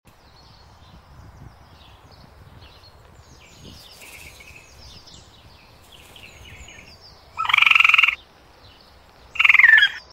Pink Winged Piluk – Lookout Behavior sound effects free download
Pink-Winged Piluk – Lookout Behavior Log From observations of fantasy creatures in the Chimeric Garden, a place that exists only in stories: At the edge of the meadow I saw a Pink-Winged Piluk acting as a lookout. From time to time it gave a call, moved to a new spot, and kept watching the area.